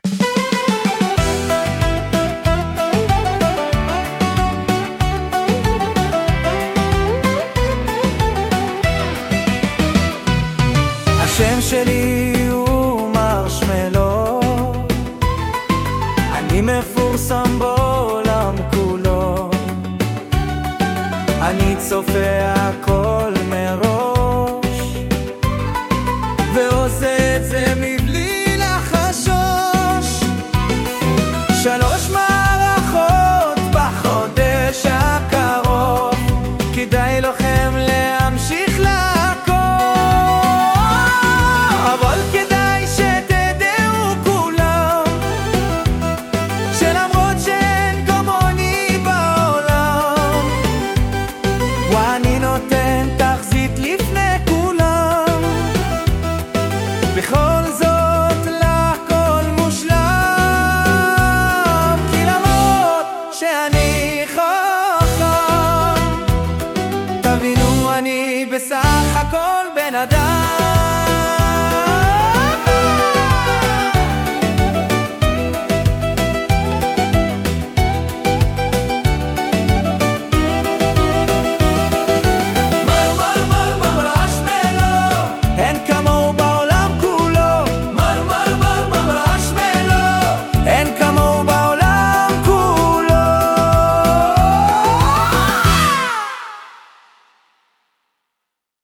יש קצת טעויות בניקוד [בינה מלכותית]
מדובר ב''זמריר'' (רינגטון) שכתבתי על התחזיות של מרשמלו, וביקשתי מה''בינה'' להלחין מנגינה לשיר, והתוצאה די חביבה (כולל השגיאות של ה''בינה'').